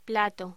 Locución: Plato
voz